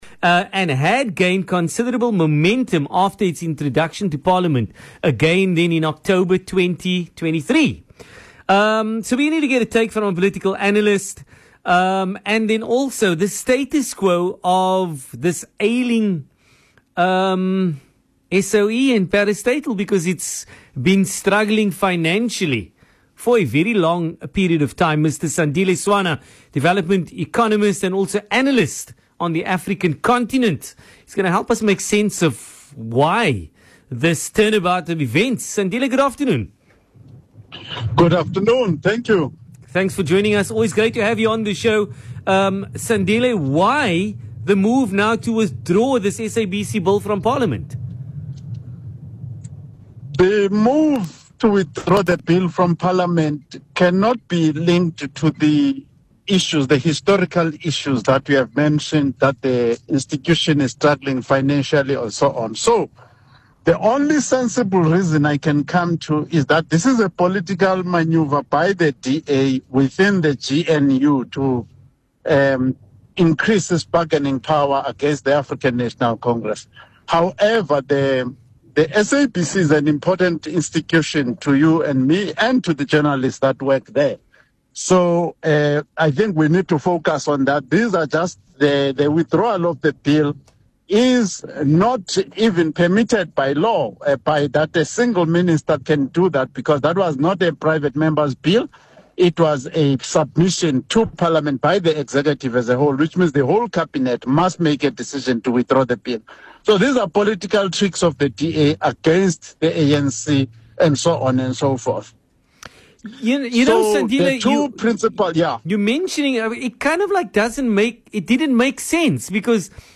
Speaking on VOC’s PM Drive show